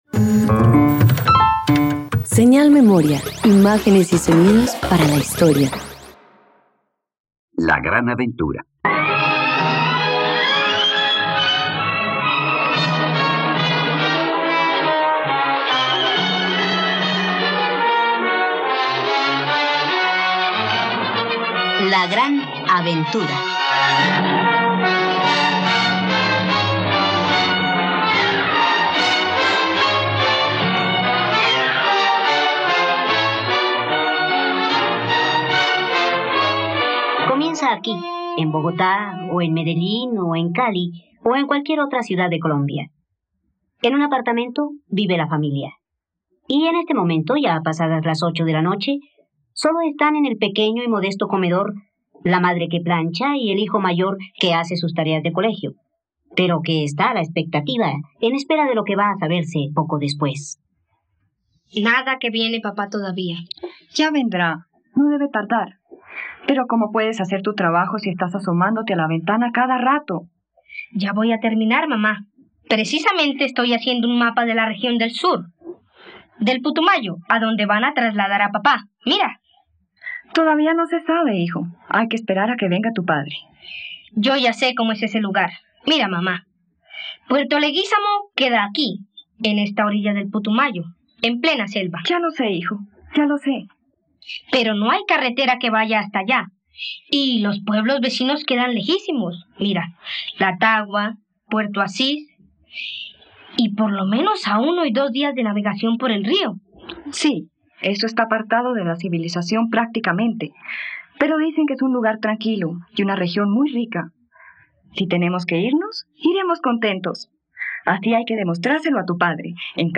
La gran aventura - Radioteatro dominical | RTVCPlay
..Radioteatro. Escucha la adaptación de la obra "La gran aventura" del escritor argentino Eduardo Pose en la plataforma de streaming RTVCPlay.